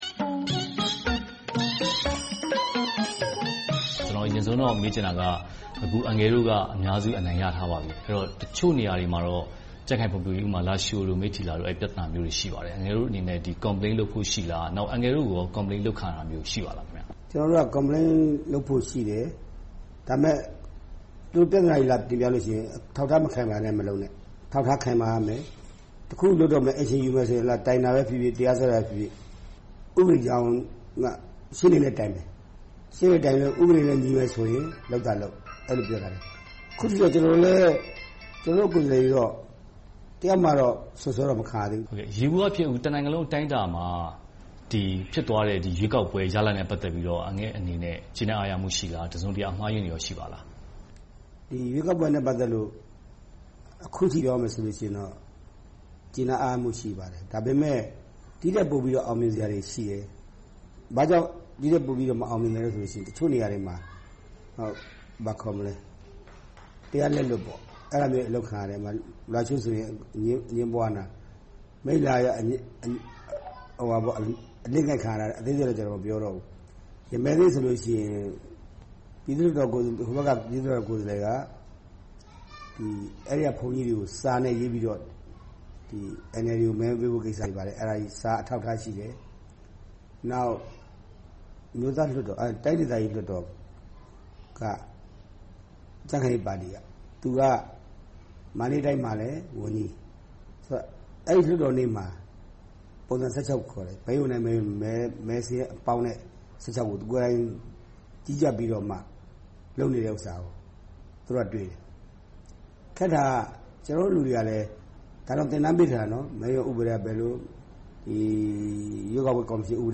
ဦးဝင်းထိန်နဲ့ တွေ့ဆုံမေးမြန်းခန်း